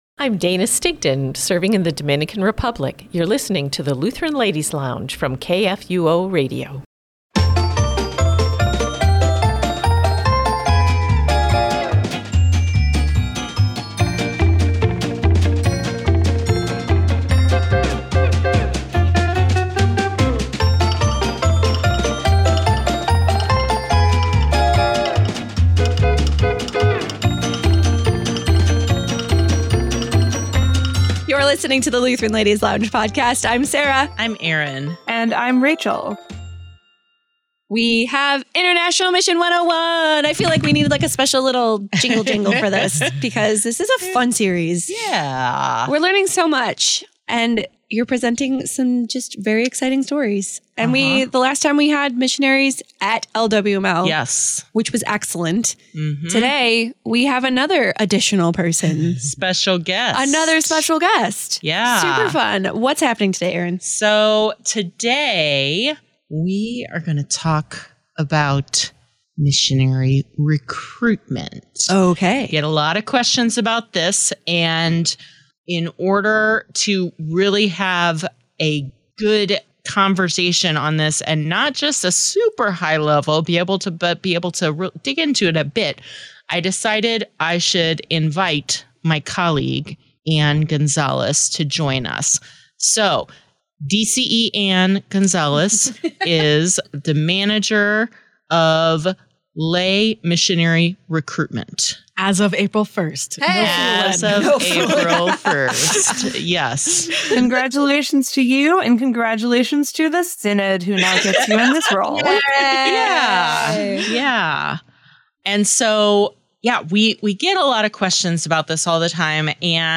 1 All Hail the Pull List: The Voice Said Kill, Storm, Mr. Terrific, and Silk Cotton😍 2:05:10 Play Pause 11h ago 2:05:10 Play Pause Play later Play later Lists Like Liked 2:05:10 Send us a text Come and hang out with us as we talk about our recent pulls, watch we’re watching, and anything else that comes up in the chats!! —--------------------- Pink Riot! is a live- streamed comic show and podcast that predominantly highlights BIPOC writers and artists in the comic book industry while creating events and opportunities that …